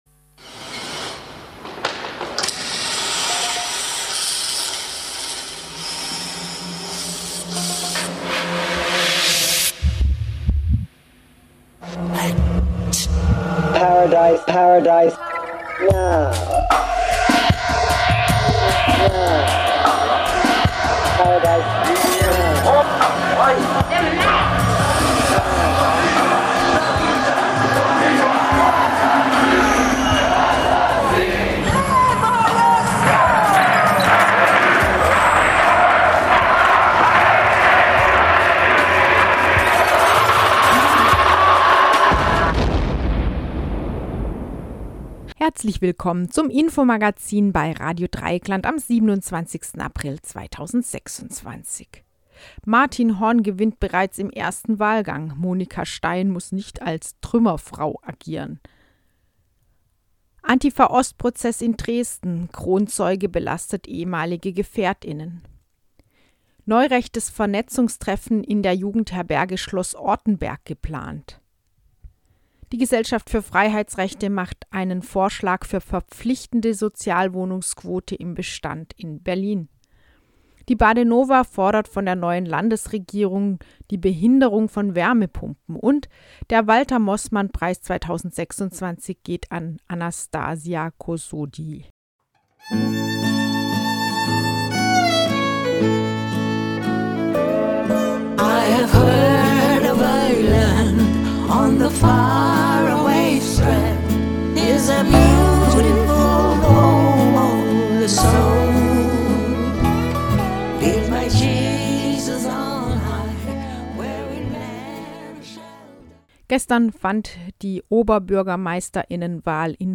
Diskussion zur Wohnungspolitik